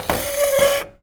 R - Foley 61.wav